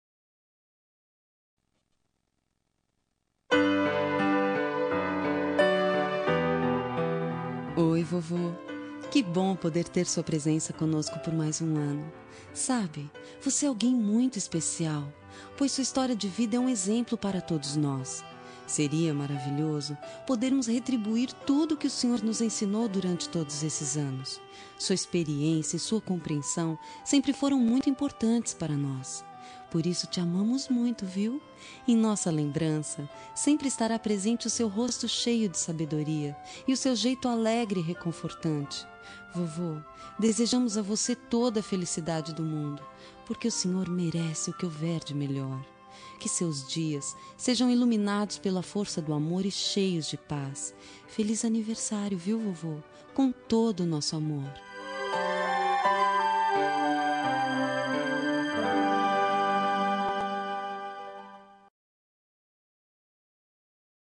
Aniversário de Avô – Voz Feminina – Cód: 2089